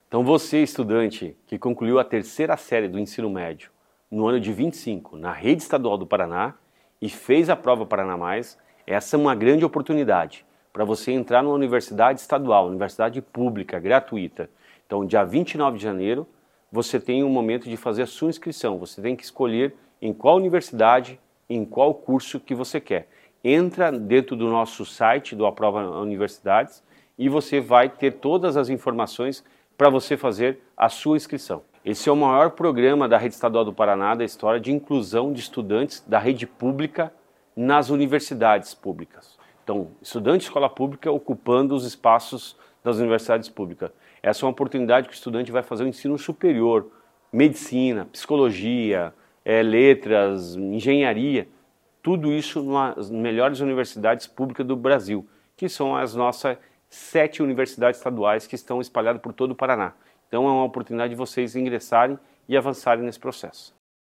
Sonora do secretário da Educação, Roni Miranda, sobre o último dia de inscrição para o Aprova Paraná Universidades